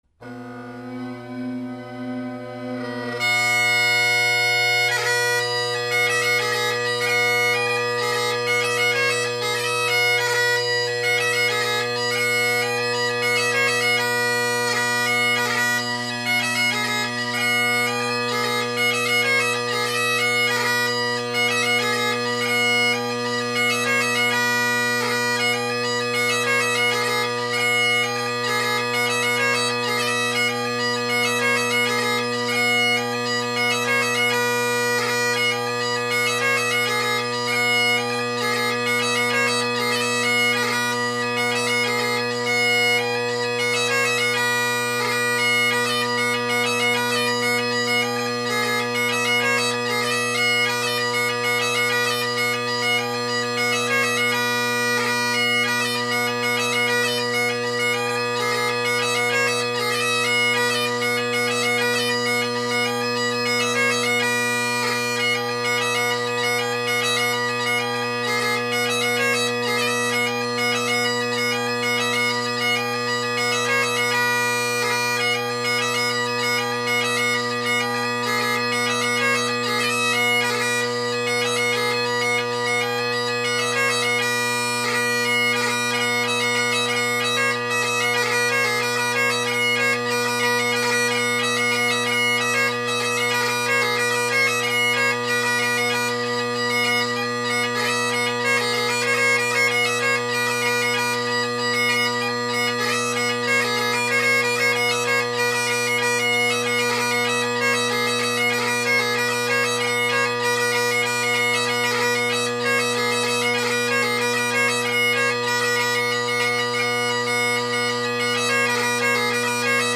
X-TREME drone reeds
Below you’ll find 3 recordings, 2 with the drones pointed at the microphone and 1 chanter side to help you get a better idea of the relative volumes.
I am also currently working on blowing out the chanter reed more, so sometimes the high A is a little flat with some crow.
Captain Calum Campbell’s Caprice, Mrs. Martha Knowles, McPherson’s Rant, and The Tourist – mic is drone side
Drone Sounds of the GHB, Great Highland Bagpipe Solo